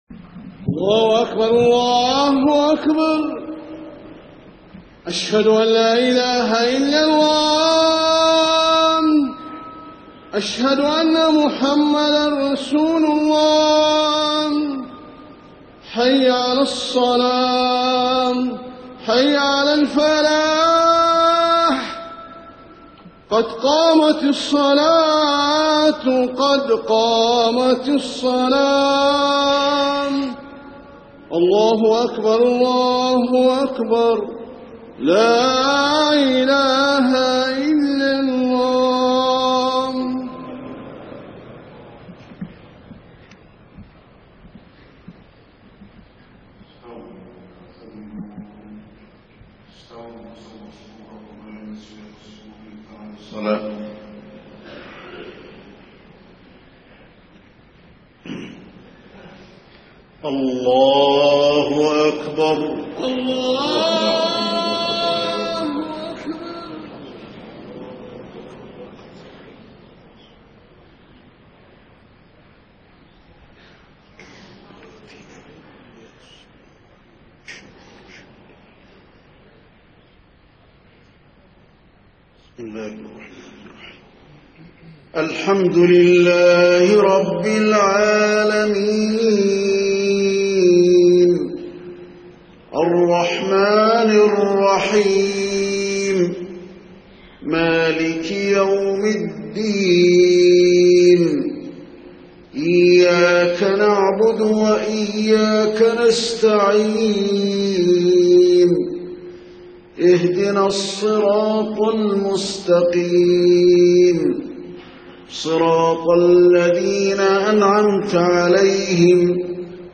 صلاة العشاء 24 محرم 1430هـ خواتيم سورة الدخان 40-59 > 1430 🕌 > الفروض - تلاوات الحرمين